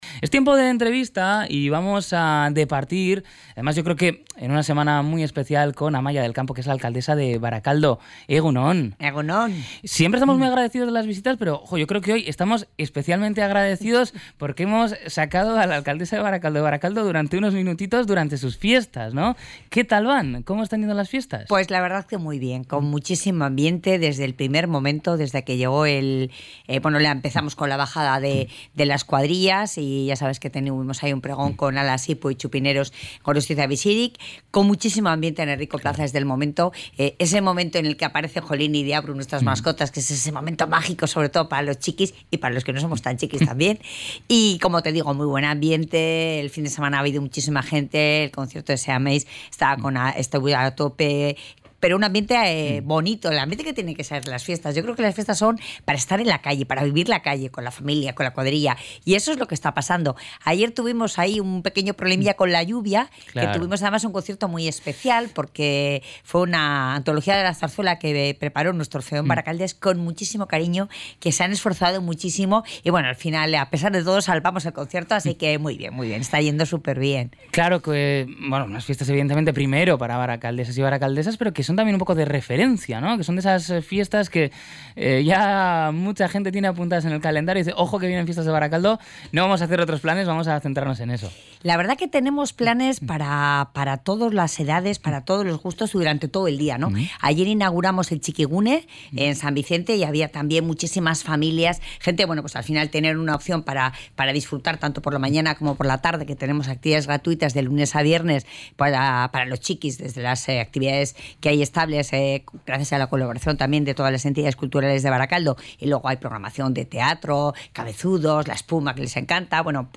Entrevista-Amaia-del-Campo.mp3